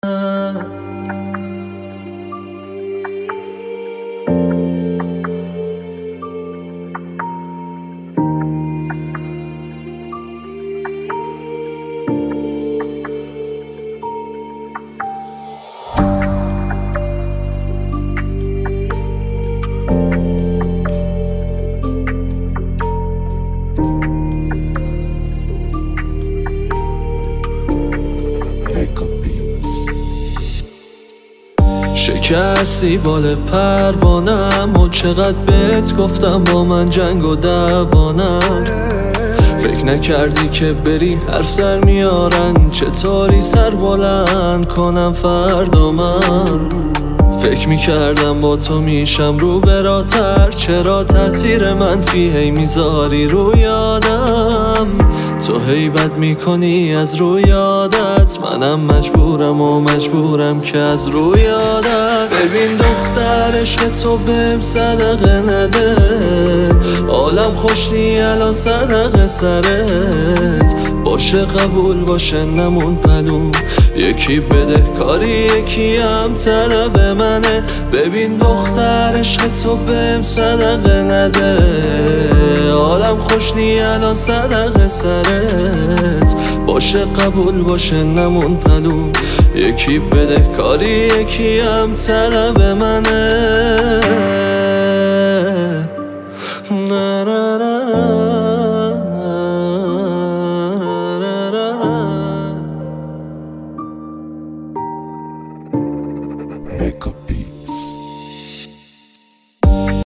آهنگ دلی